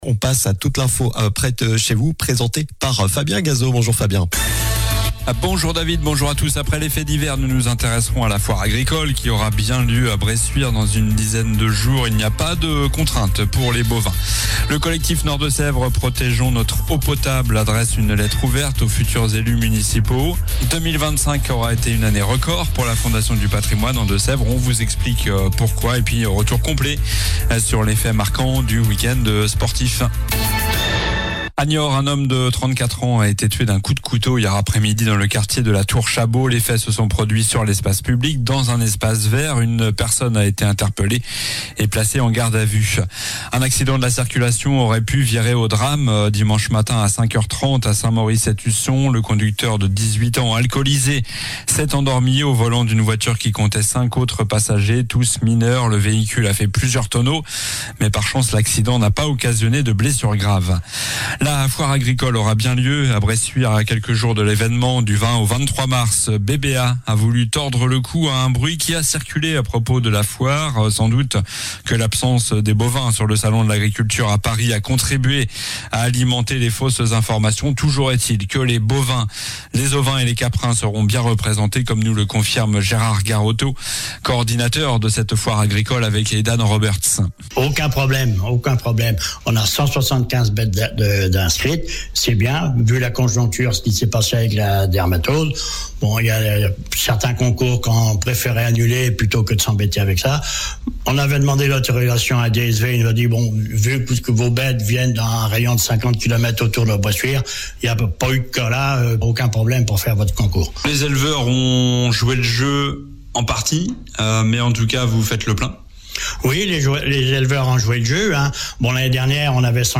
Journal du lundi 9 mars (midi)